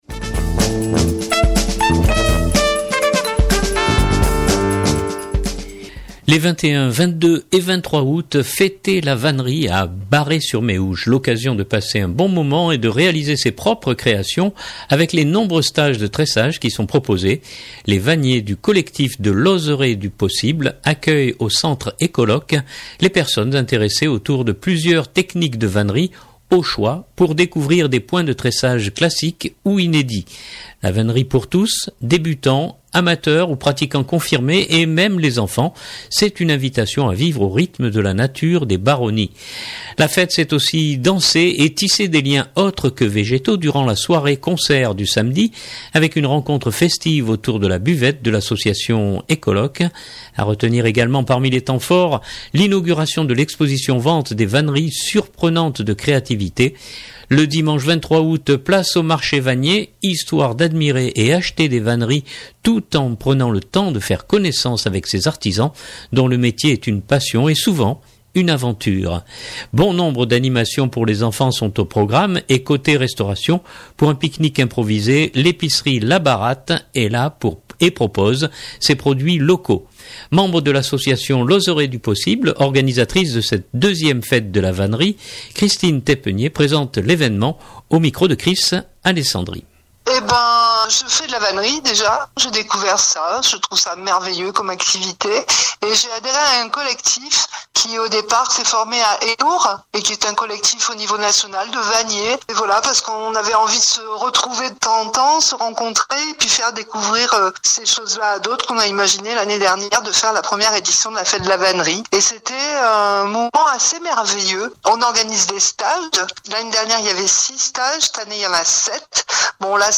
présente l’événement au micro